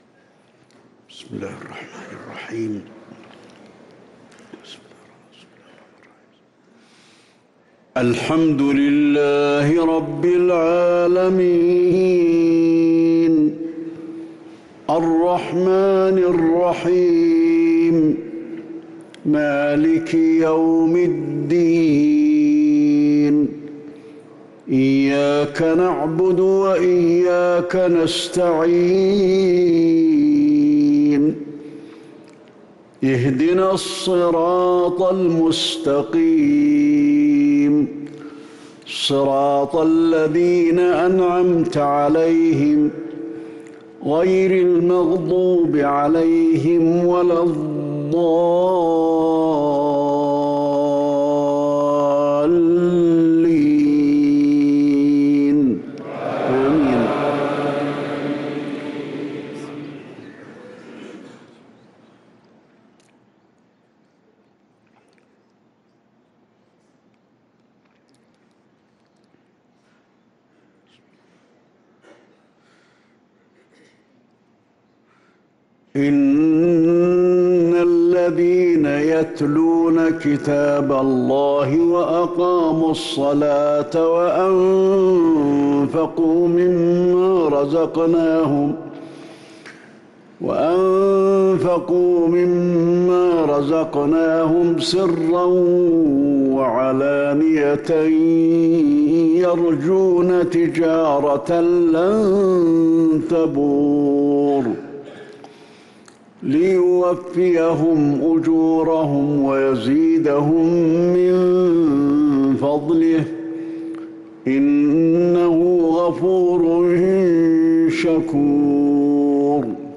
صلاة المغرب للقارئ علي الحذيفي 19 رمضان 1444 هـ